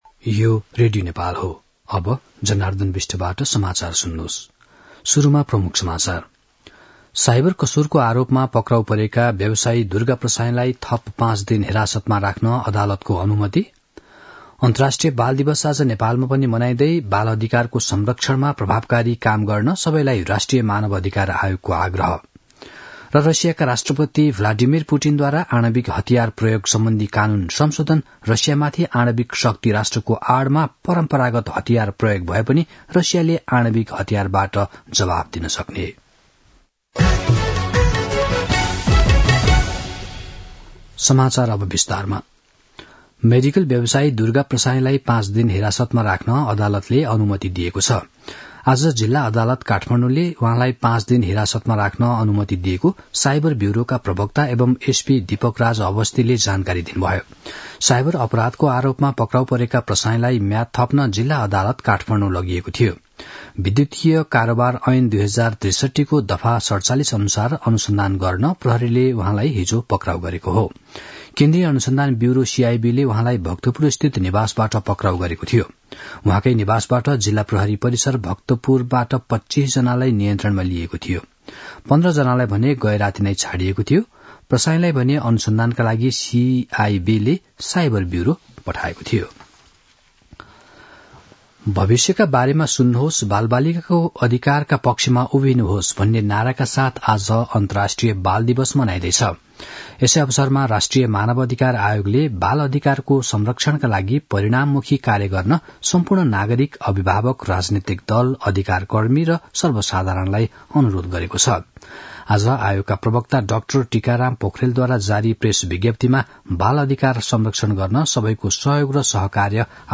दिउँसो ३ बजेको नेपाली समाचार : ६ मंसिर , २०८१
3-pm-nepali-news-1-4.mp3